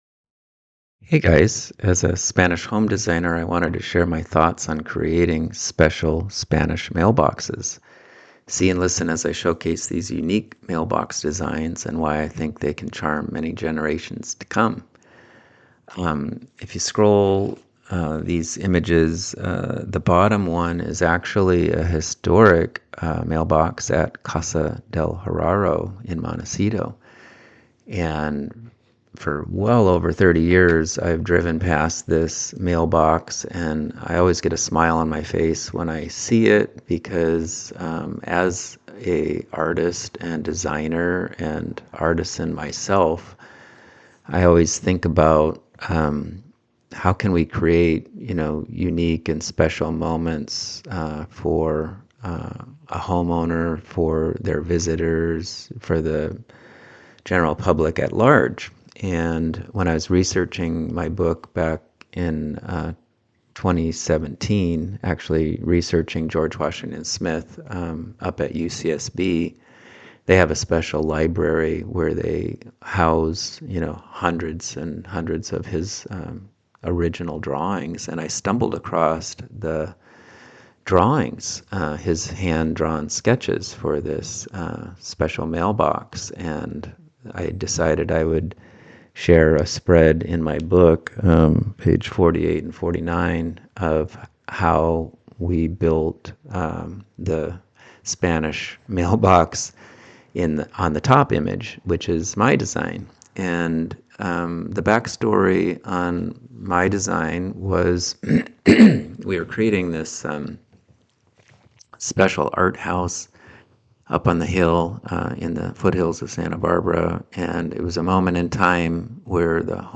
Listen in as I talk about the impetus behind this custom Spanish mailbox design, and the history of creative mailbox designs in Montecito and Santa Barbara CA.